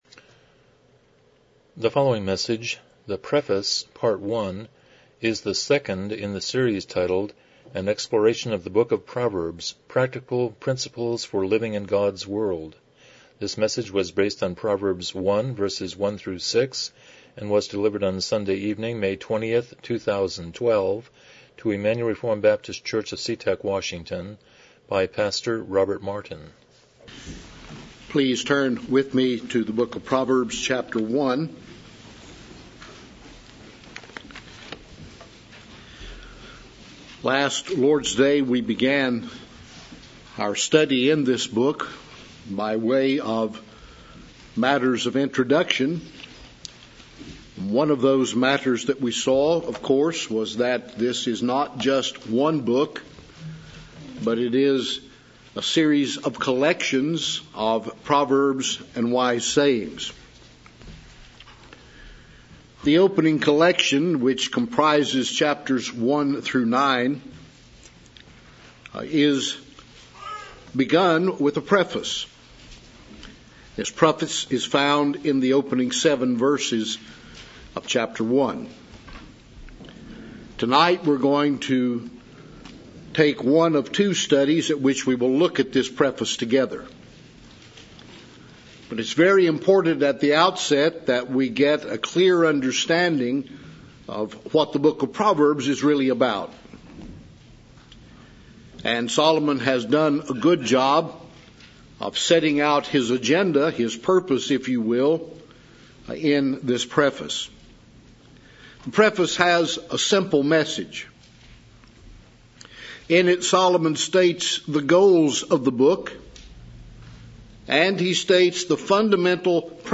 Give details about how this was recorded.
Passage: Proverbs 1:1-5 Service Type: Evening Worship